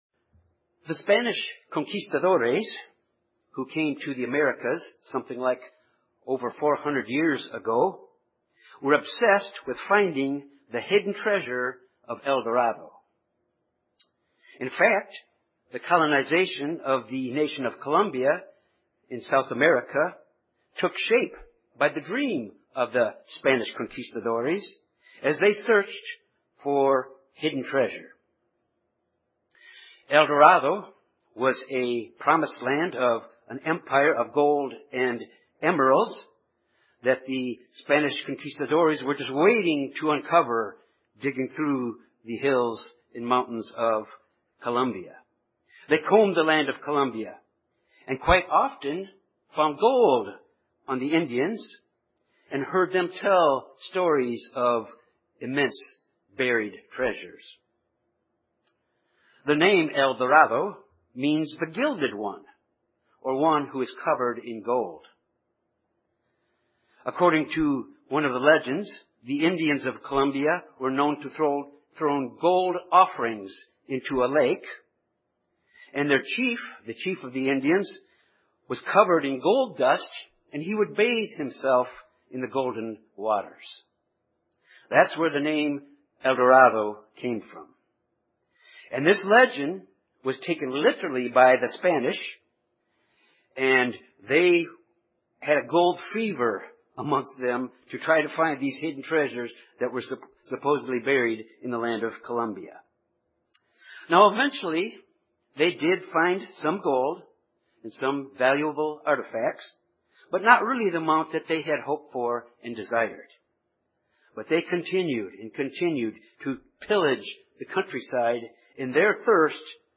Jesus Christ compared the knowledge of the Kingdom of God to a treasure that is hidden in a field. This sermon looks at how precious this knowledge of the Kingdom is to us.